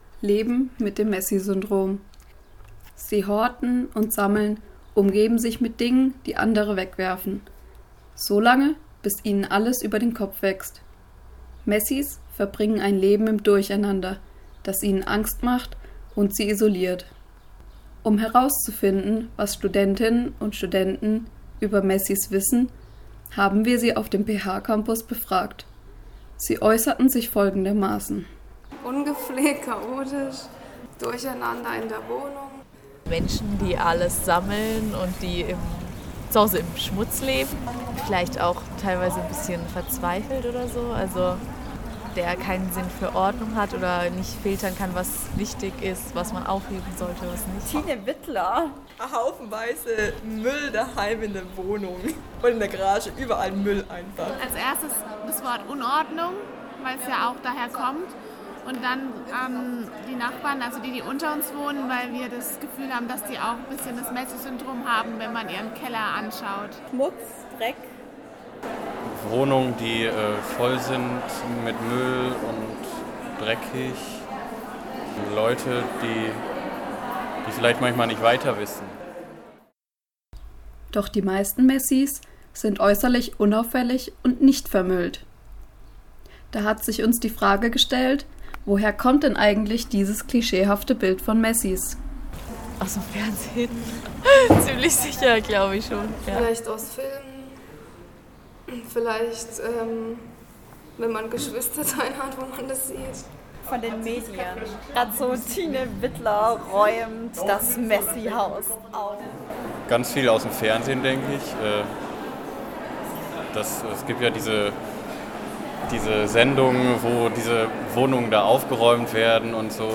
Stimmen auf dem Campus zum Messie-Syndrom
stimmen-auf-dem-campus-zum-messie-syndrom.mp3